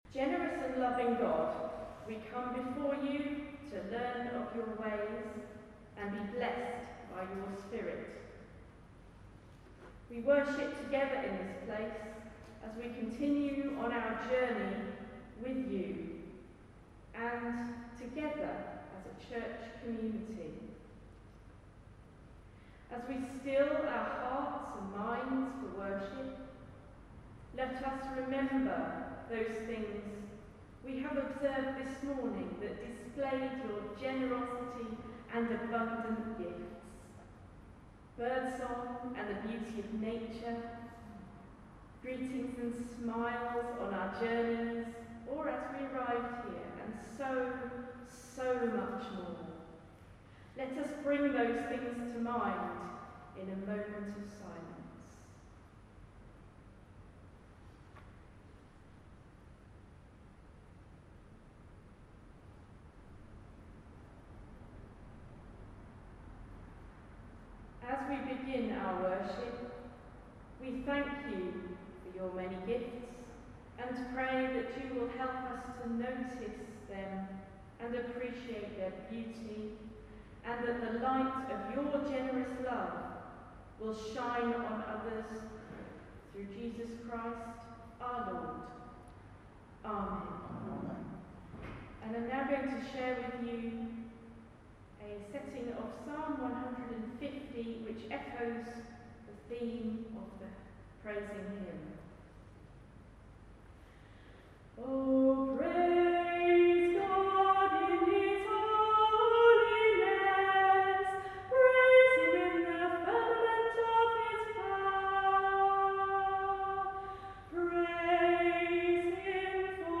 I've started training to become a Methodist local preacher and, as part of my training, I'm participating more in Sunday services. Here are my opening prayers, including a sung version of Psalm 150.